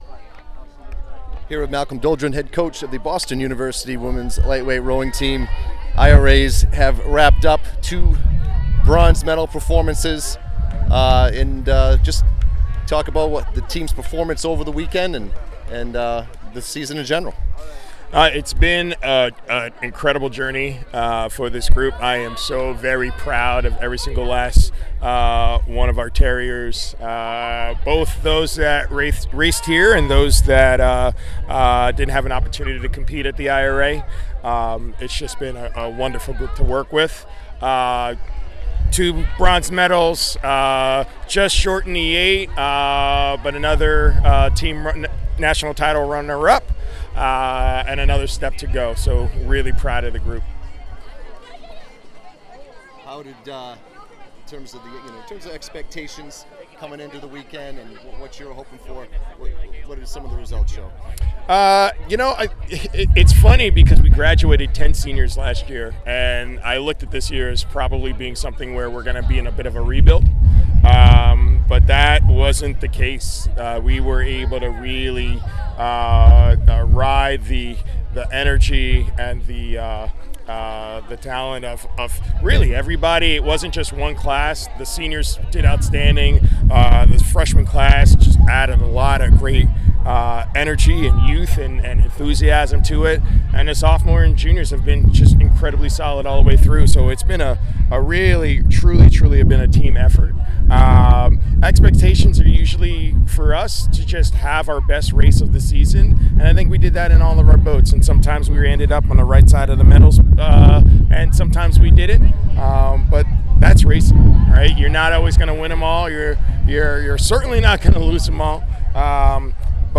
IRA Championship Postrace Interview